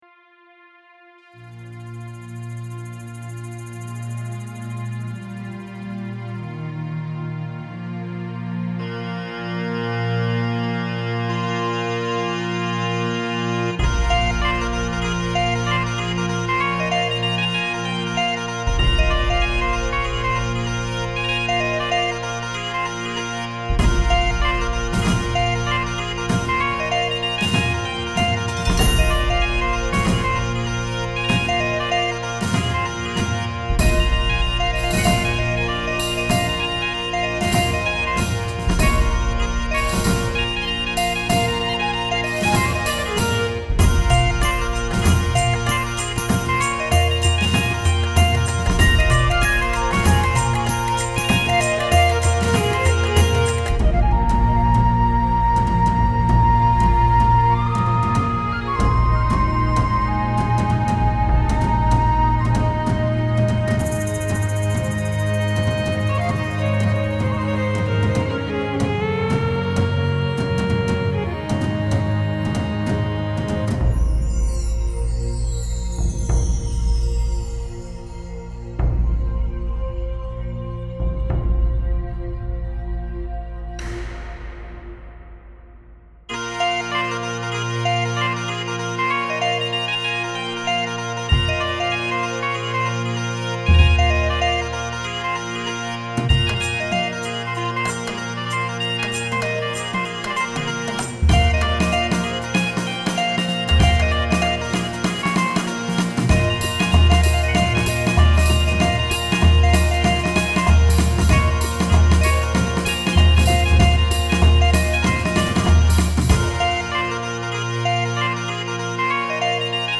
Bagpipes - Reality Bytes